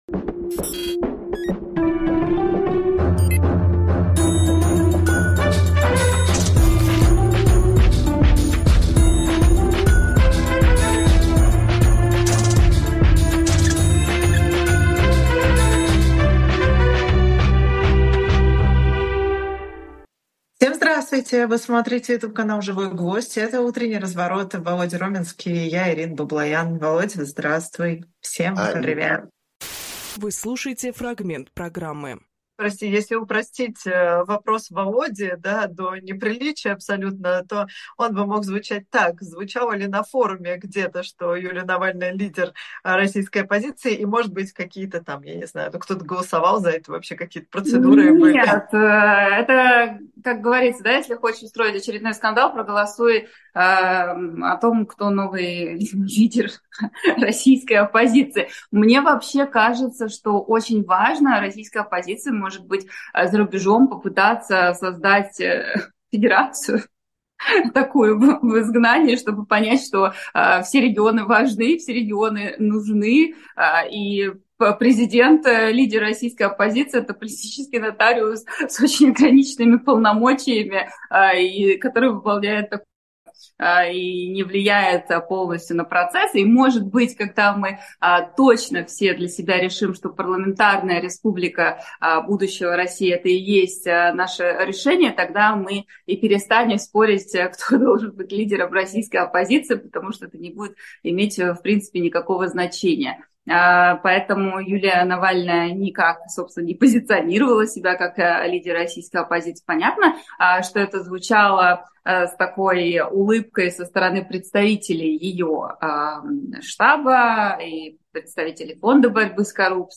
Фрагмент эфира от 12 ноября.